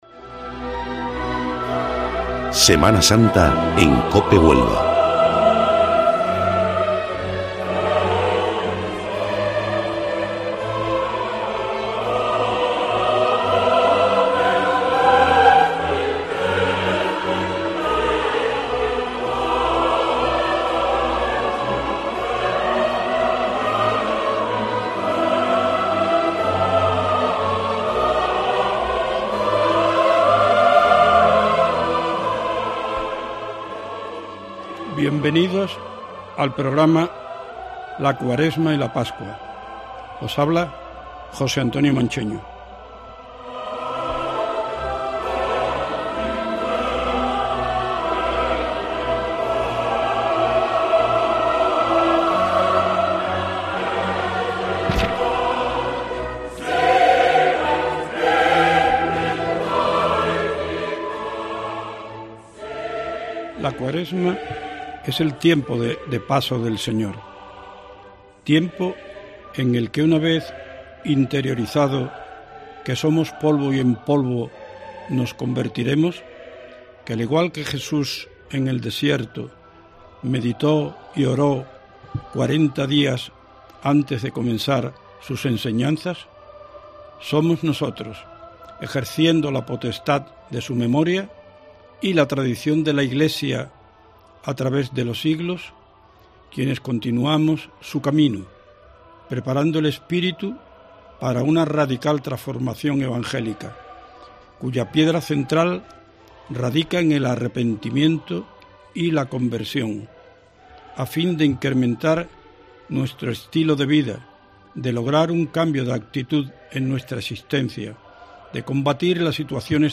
La cuaresma es el tiempo de paso del Señor, tiempo en el que se nos recuerda que somos polvo y en polvo nos convertiremos. Hoy entrevistamos